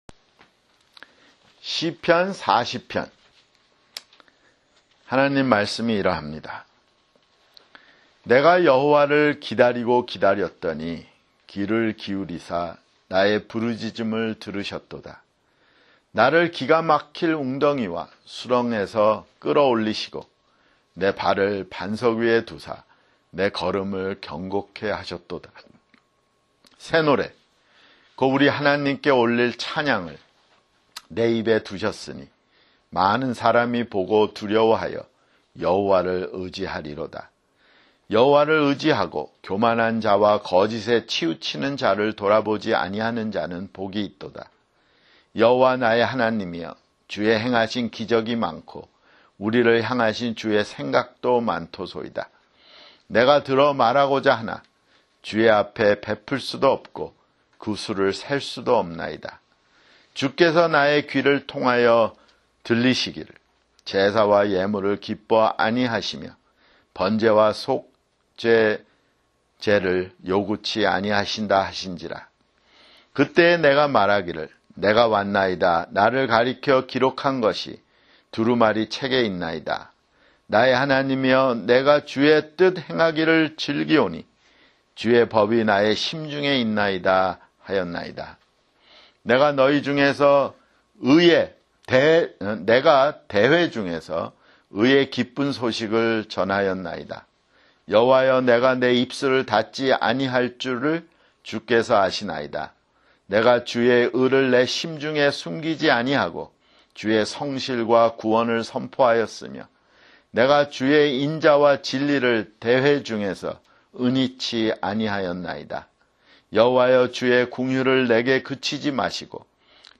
[주일설교] 시편 (32)